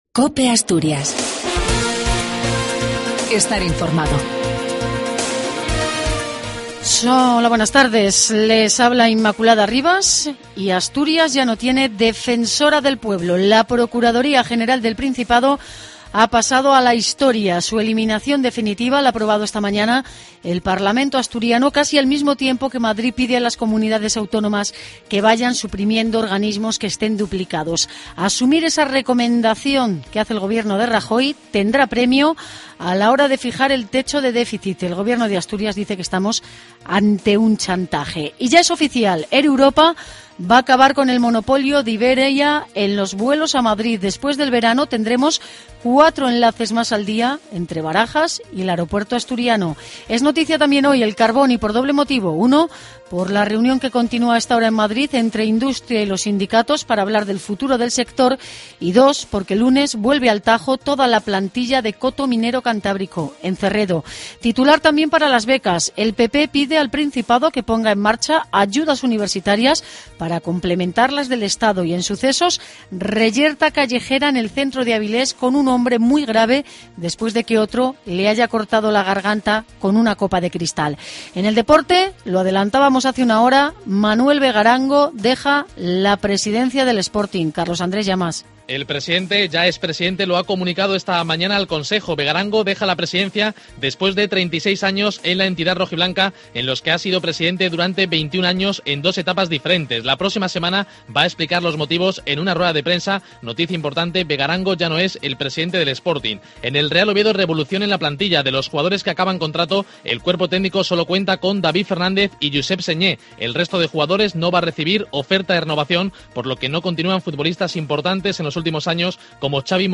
AUDIO: LAS NOTICIAS DE ASTURIAS AL MEDIODIA.